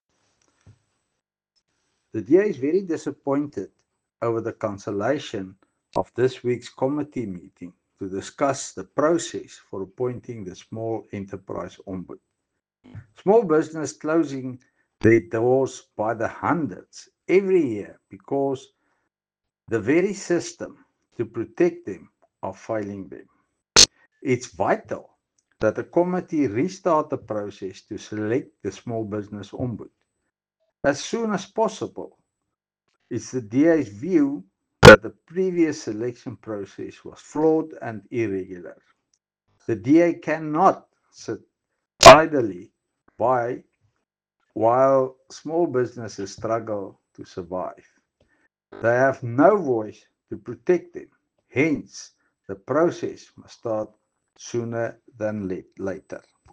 Afrikaans soundbite by Henro Kruger MP.